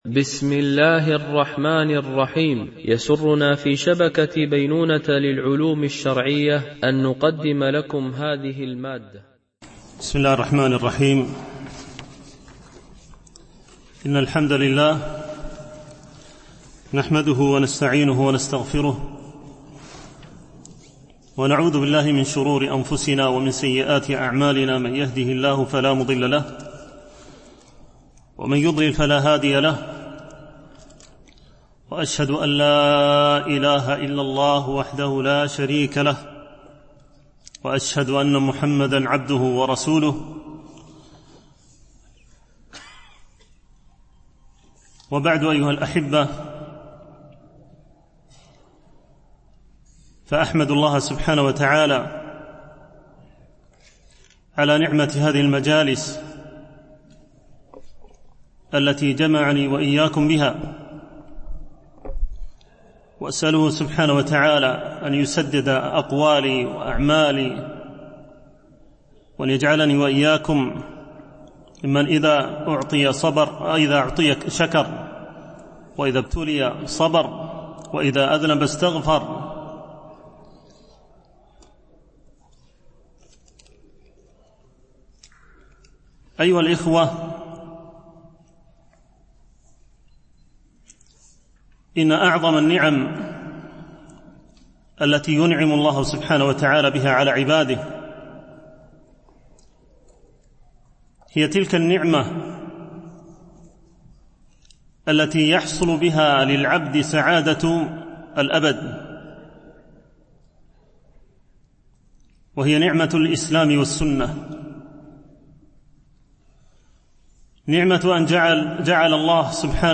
دورة علمية شرعية، بمسجد أم المؤمنين عائشة - دبي (القوز 4)